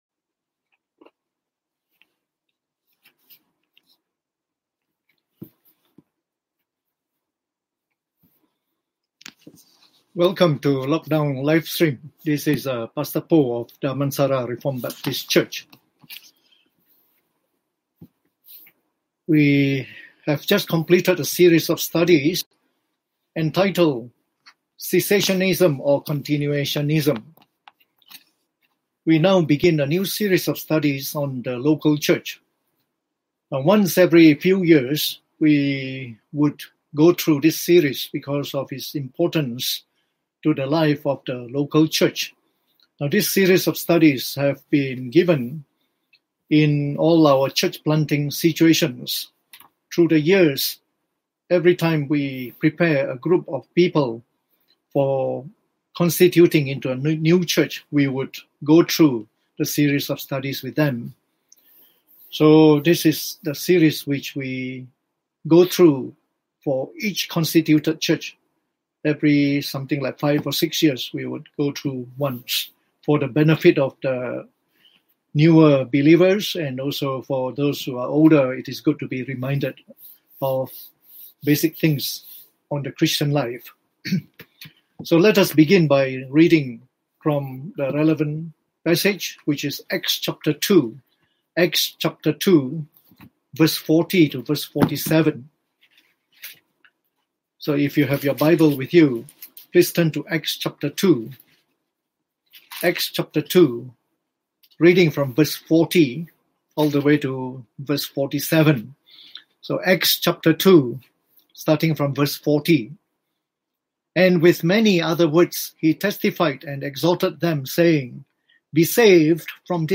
Preached on the 20th of May 2020 during the Bible Study on The Local Church